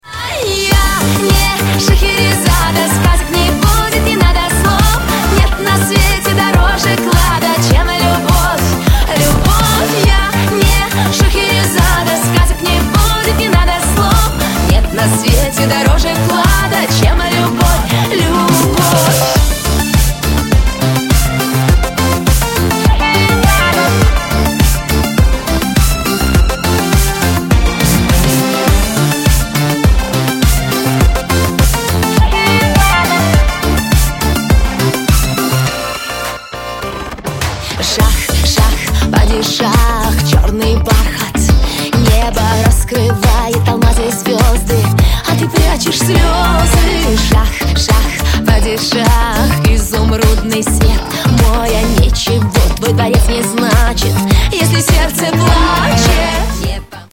• Качество: 128, Stereo
поп
dance
vocal